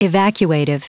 Prononciation du mot evacuative en anglais (fichier audio)
evacuative.wav